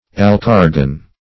Alkargen \Al*kar"gen\, n. [Alkarsin + oxygen.]